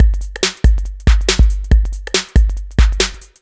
140 Ass Track Full.wav